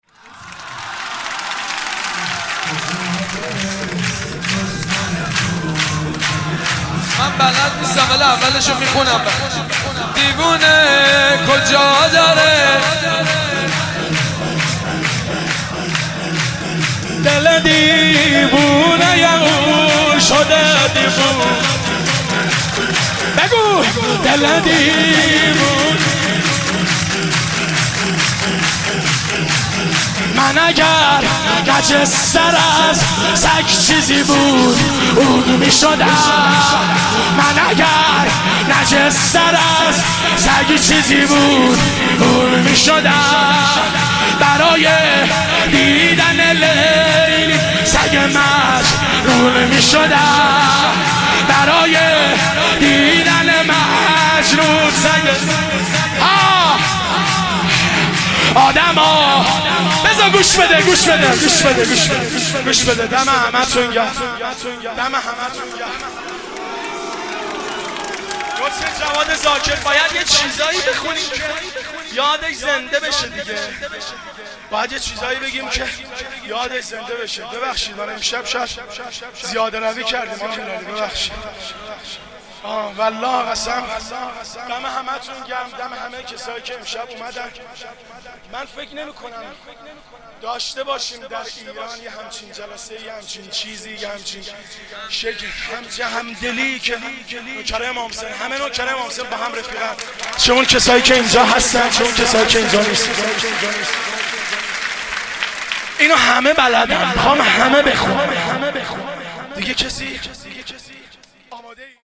مناسبت : ولادت حضرت علی‌اکبر علیه‌السلام
قالب : شور